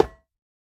latest / assets / minecraft / sounds / block / iron / break1.ogg